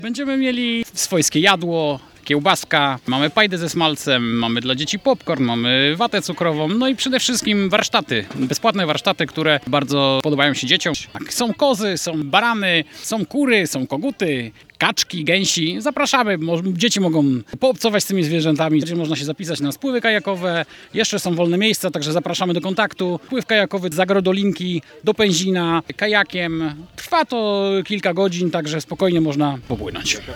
Dziś półmetek Pikniku Majowego w Agrodolince w podstargardzkim Gogolewie.